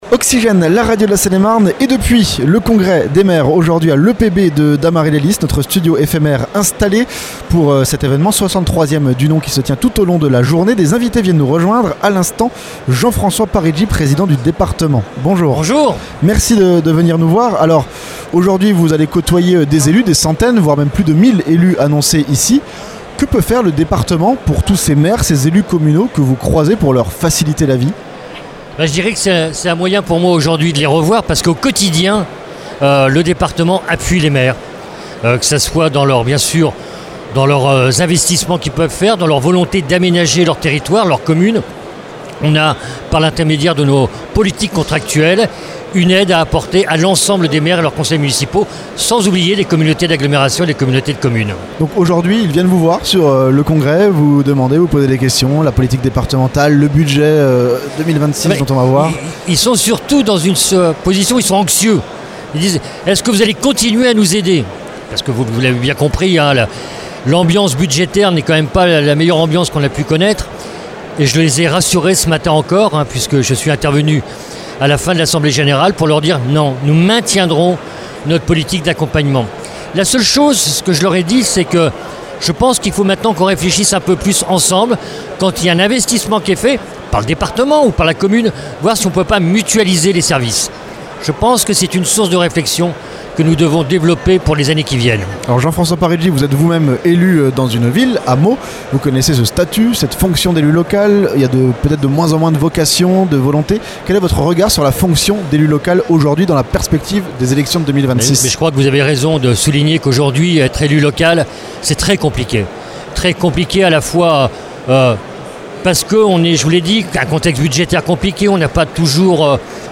A l'occasion du 63ème congrès des maires de Seine-et-Marne, à Dammarie-lès-lys, Oxygène s'est entretenu avec Jean-François Parigi, Président du Conseil départemental de Seine-et-marne.